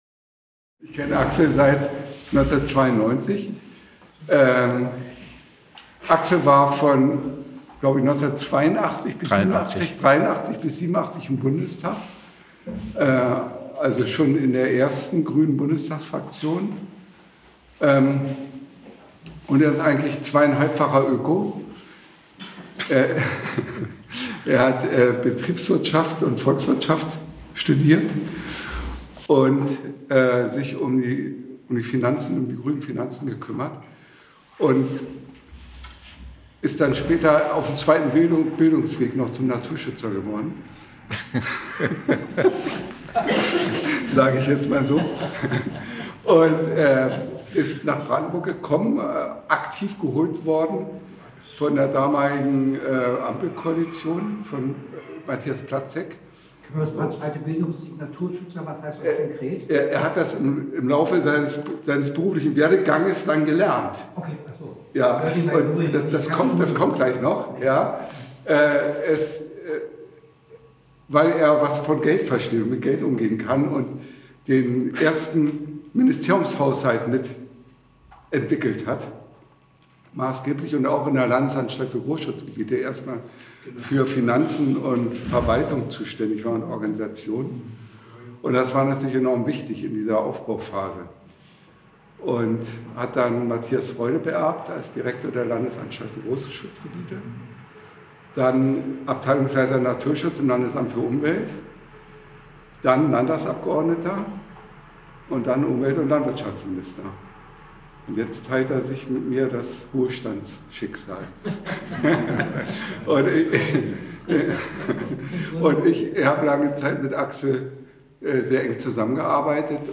Publikumsgespräch mit Axel Vogel